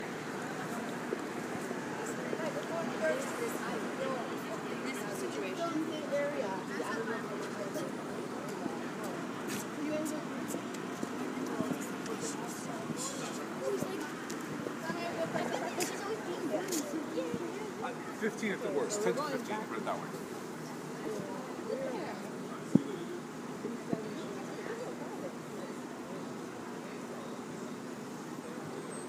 street – Hofstra Drama 20 – Sound for the Theatre
Corner of Taras Shevchenko Pl. and 7th St. 4/16/16 2:03 pm
Cars driving by, various people (men and women) walking and talking, slight wind, shuffling of shoes
Field-Recording-3.mp3